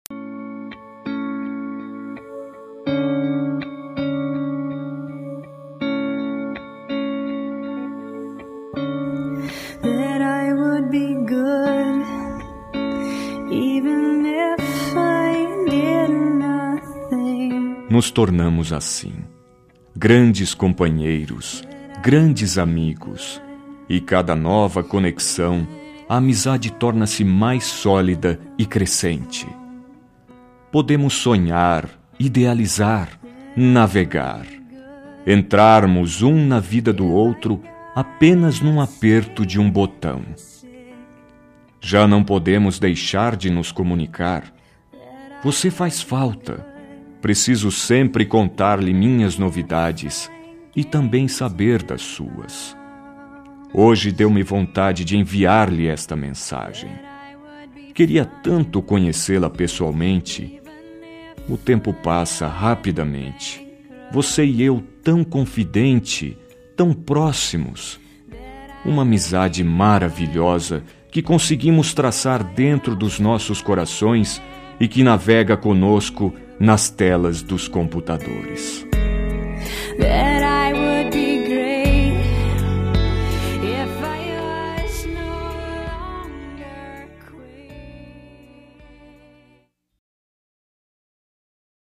Telemensagem Virtual – Voz Masculina – Cód: 60213